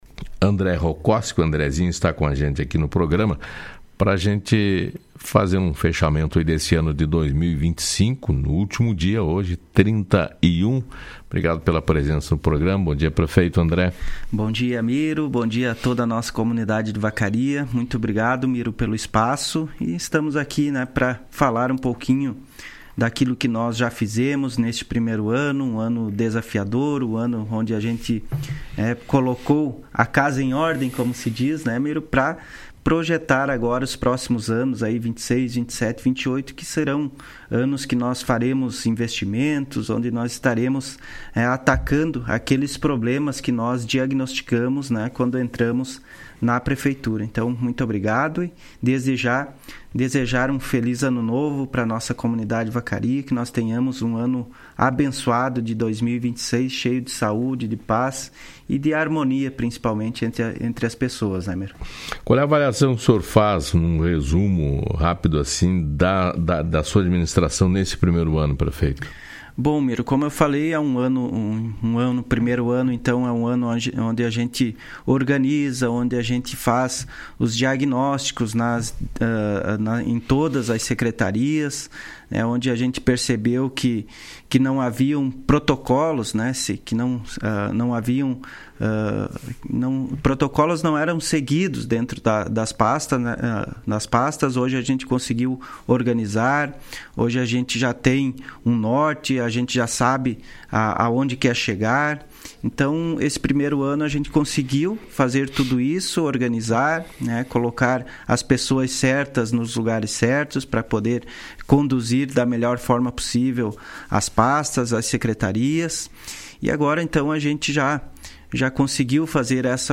O prefeito de Vacaria, André Rokoski, participou do programa Fala Cidade deste último dia do ano de 2025 e fez um balanço do seu primeiro ano de administração.
ENTREVISTA-PREFEITO-31-12.mp3